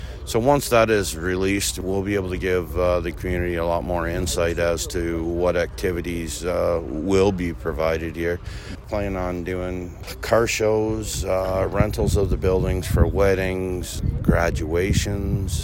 There was a sod-turning ceremony at the new home along Highway 37 and Black Diamond Road.